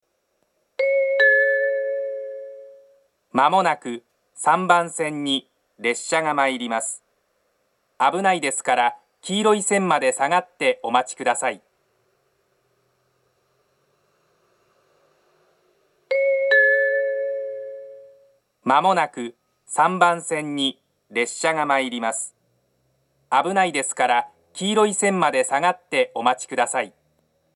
接近放送の流れるタイミングは早くなく、接近表示機が点滅してから録音をはじめても十分に間に合います。
その後すぐに設定を変更したようで、２０１９年３月下旬には上下で放送の男女が入れ替わり、言い回しも変更されています。
３番線下り接近放送
minami-sendai-3bannsenn-kudari-sekkinn1.mp3